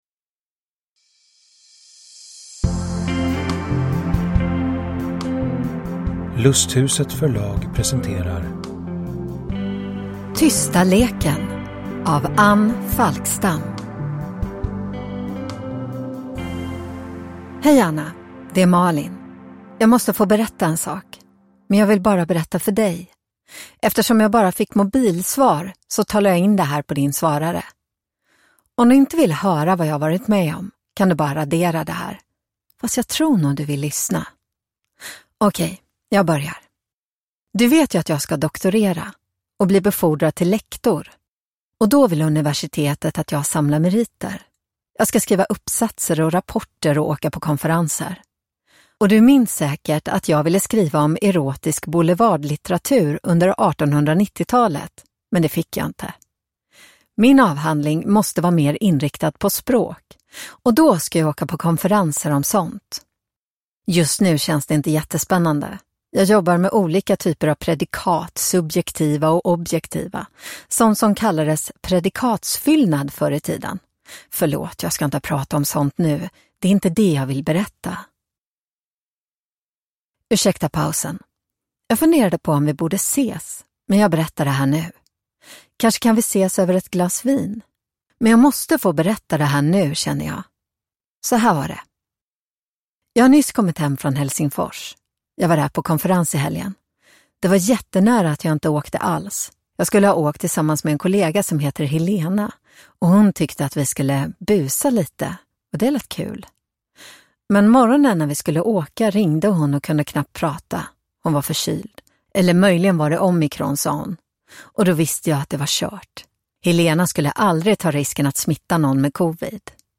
Tysta leken (ljudbok) av Anne Falkstam